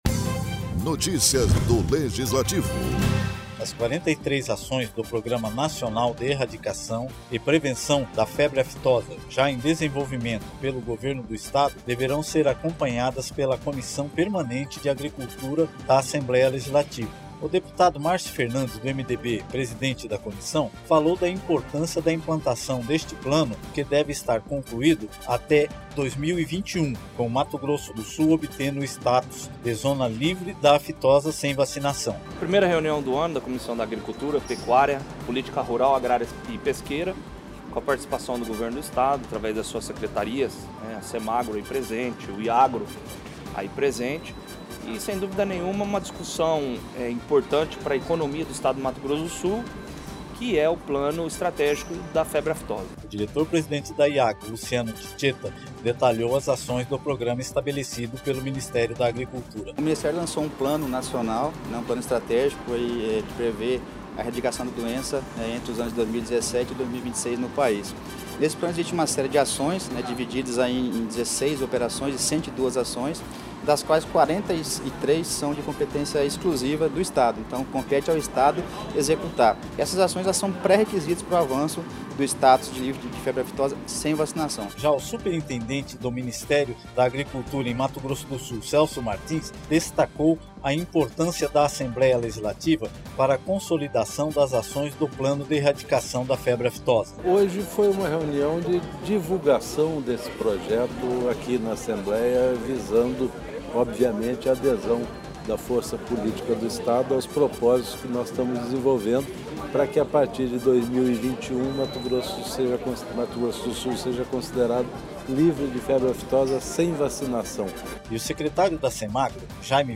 As 43 ações do Plano Nacional de Erradicação da Febre Aftosa já em desenvolvimento pelo Governo do Estado deverão ser acompanhadas pela Comissão de Agricultura, Pecuária e Políticas Rural, Agrária e Pesqueira da Assembleia Legislativa. O deputado Marcio Fernandes, do MDB presidiu na tarde desta segunda-feira a primeira reunião e falou da importância da implantação deste plano que deve estar concluído até 2021, com Mato Grosso do Sul obtendo o status de zona livre da aftosa sem vacinação.